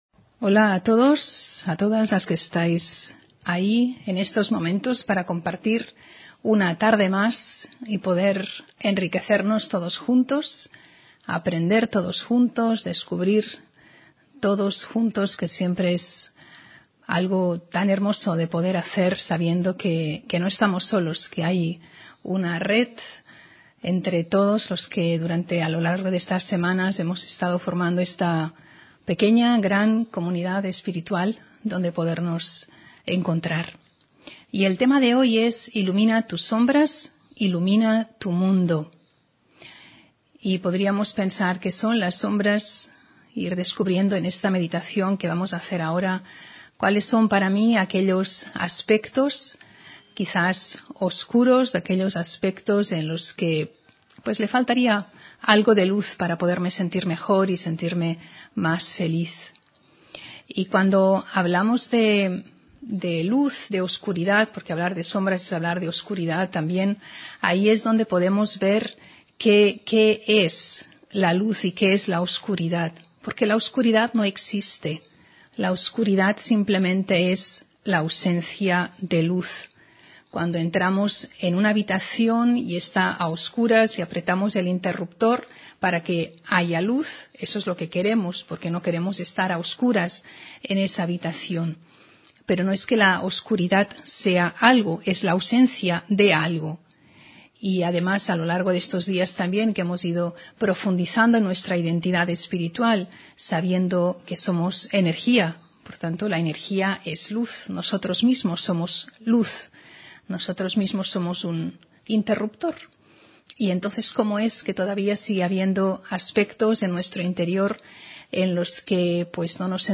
Audio conferencias
Meditación Raja Yoga: Ilumina tu sombra, ilumina tu mundo (25 Mayo 2020) On-line desde Barcelona